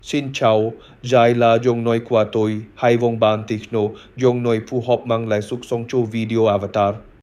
🌍 Multilingual👨 Мужской
Пол: male